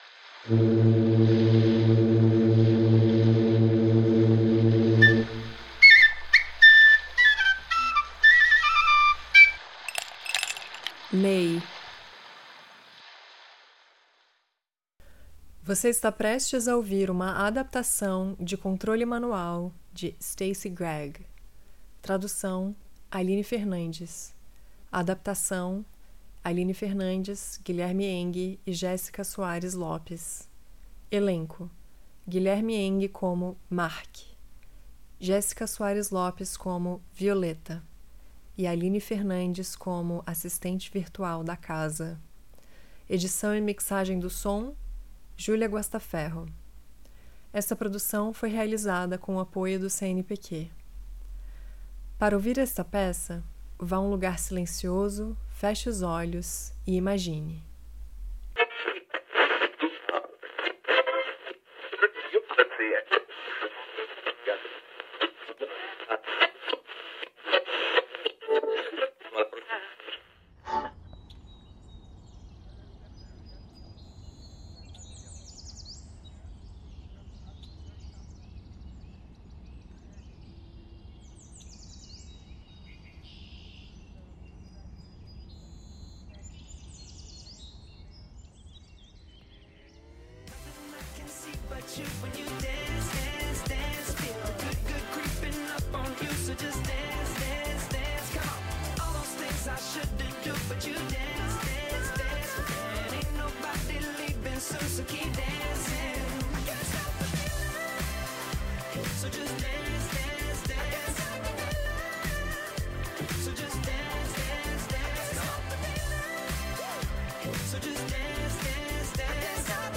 Category Radio Drama